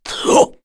Dakaris-Vox_Attack3_kr.wav